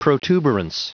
Prononciation du mot protuberance en anglais (fichier audio)
Prononciation du mot : protuberance